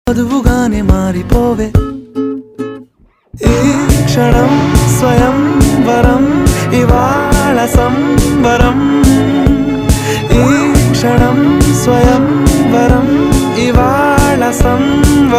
Categories: Telugu Ringtones